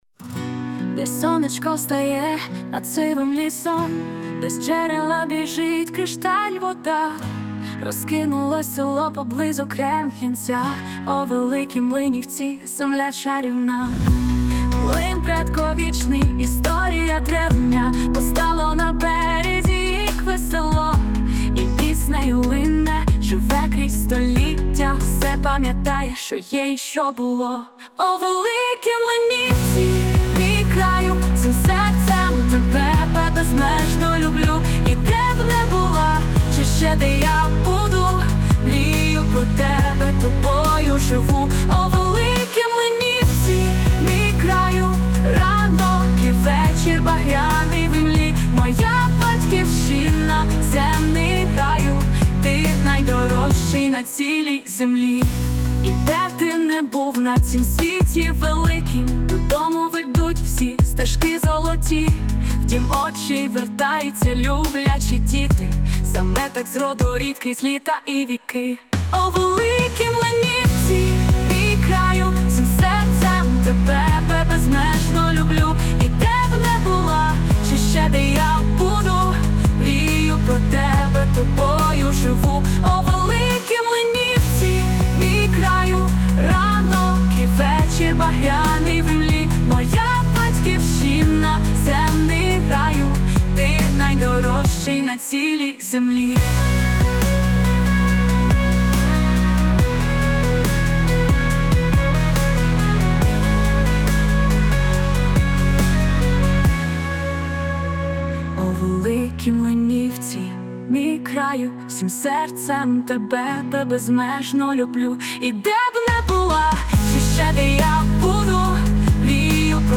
(пісня)
СТИЛЬОВІ ЖАНРИ: Ліричний
ВИД ТВОРУ: Гімн